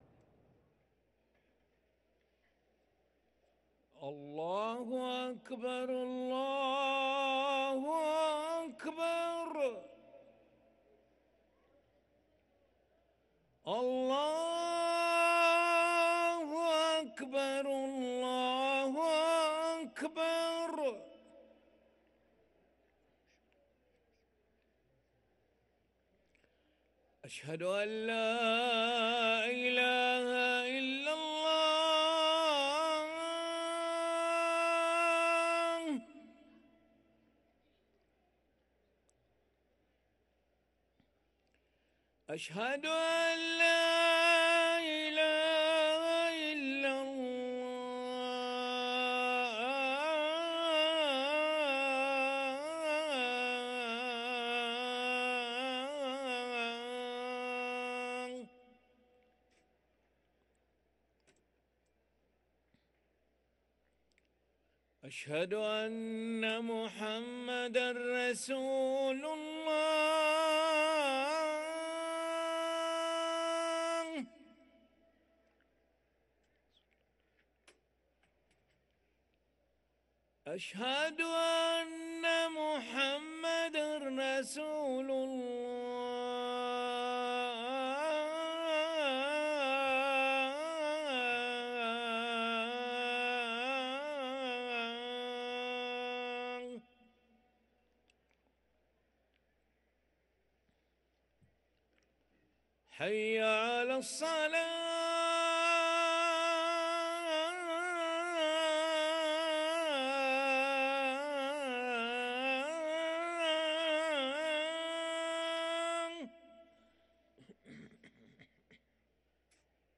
أذان العشاء للمؤذن علي ملا الخميس 21 شوال 1444هـ > ١٤٤٤ 🕋 > ركن الأذان 🕋 > المزيد - تلاوات الحرمين